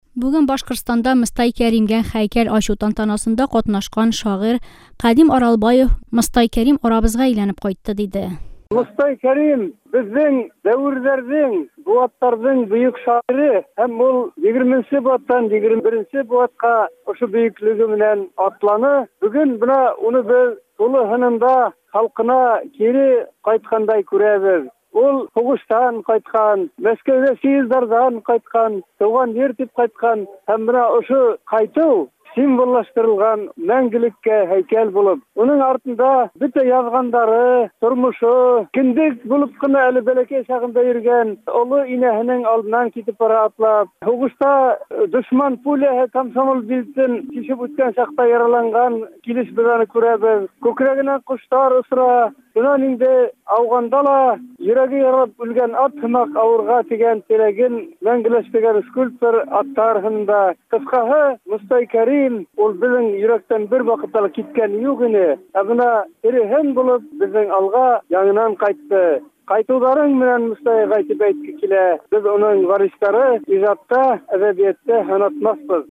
Мостай Кәримгә һәйкәл ачылышында Кадим Аралбаев чыгышы